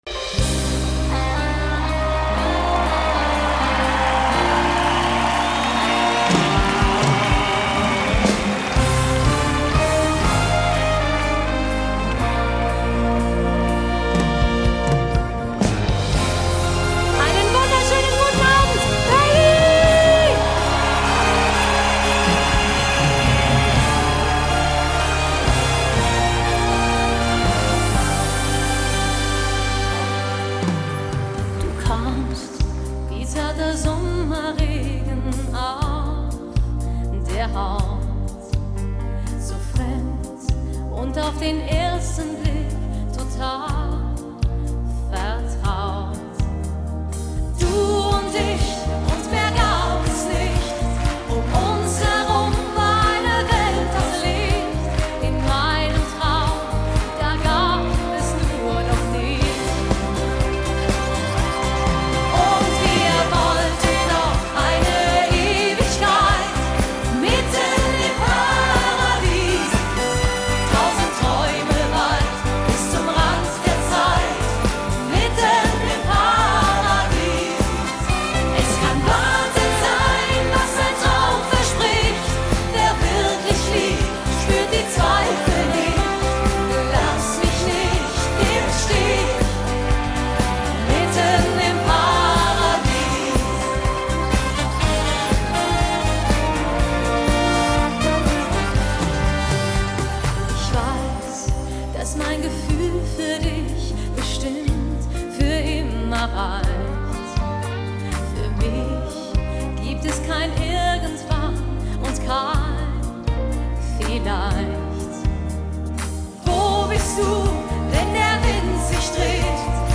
Genre: Pop, Schlager